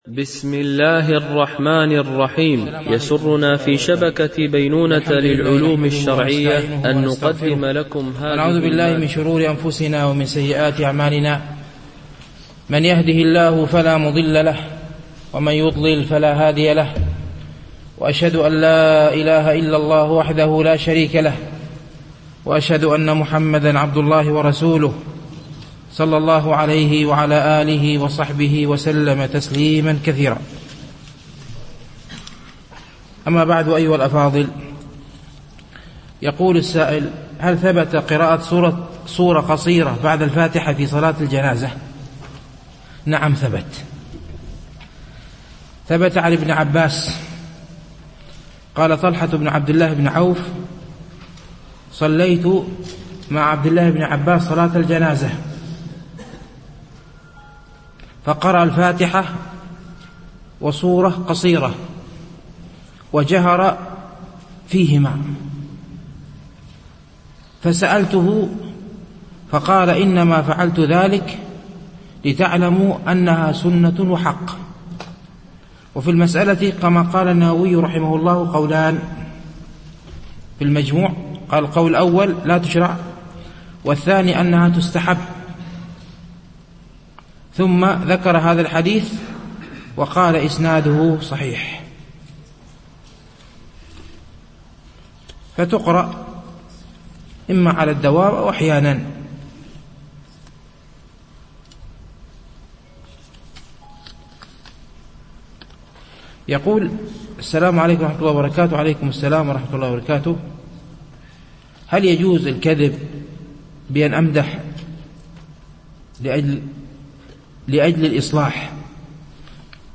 شرح رياض الصالحين - الدرس 189
التنسيق: MP3 Mono 22kHz 32Kbps (CBR)